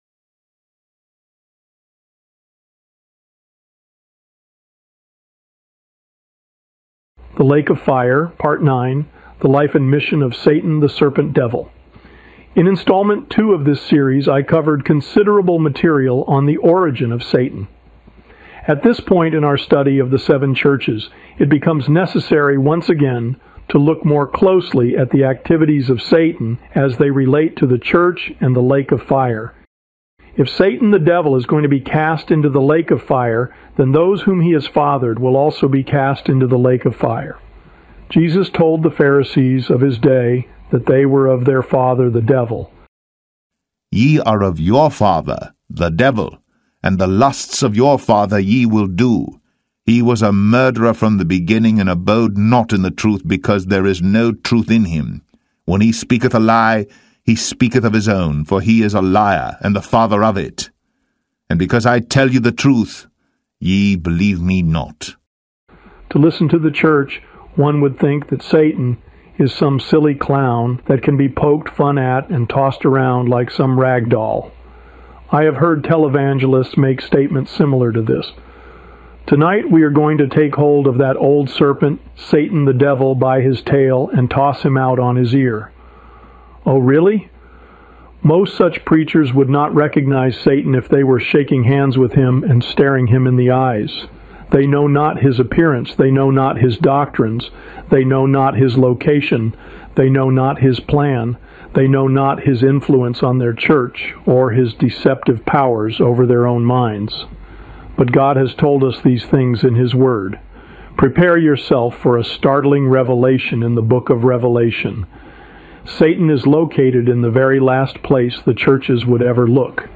YouTube Text to Speech Audio's
There will be several seconds of no sound at the start of most audio's because of the AI Text-To-Speech message at the start of each video.